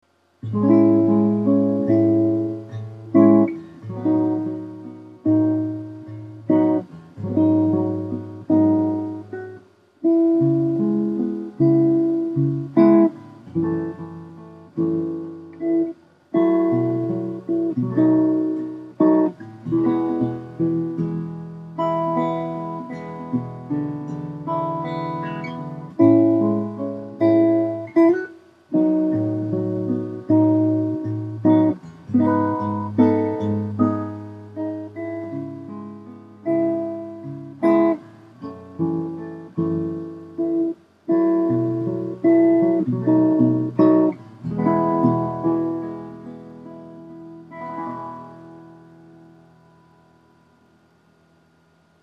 この音源は加工等一切しておりませんので、多少お聞き苦しい点もあるかと思いますが、何度か繰り返し聞いていただきたいと思います。
3. マグネットマイクのみのサウンド
1と2の中間の音で、弦の音とエアー感がうまくMixされていると思います。